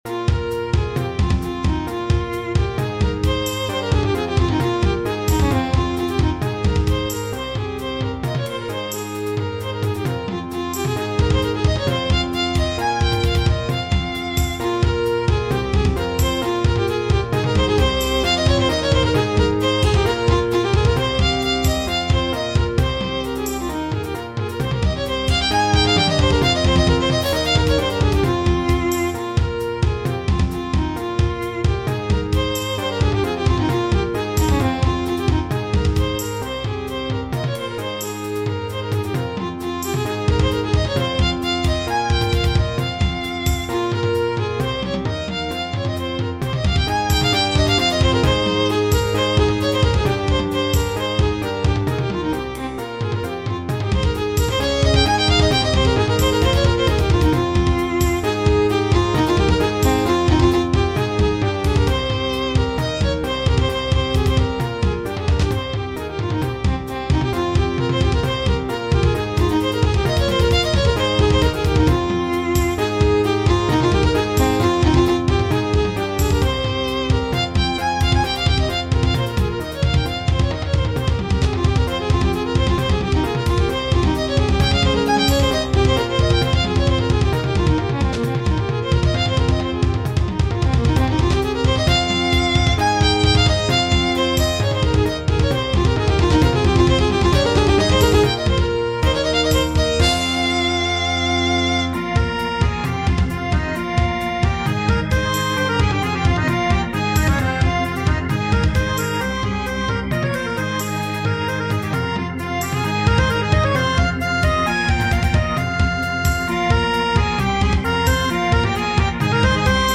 This i think is still in the chamber music category. Drums are not obligatory. i added Word "scifi", since some of the parts gives futuristic feeling.